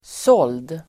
Uttal: [sål:d]